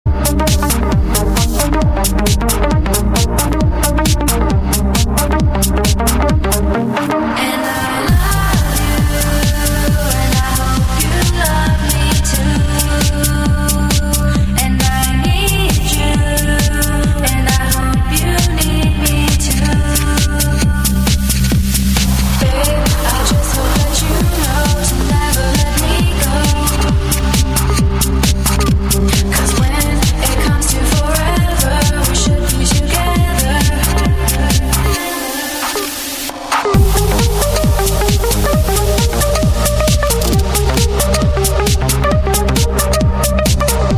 • Trance-iness: Yes
• Progressive-ness: Yes
• Dance-iness/Intensity: Medium
• Vocals: Female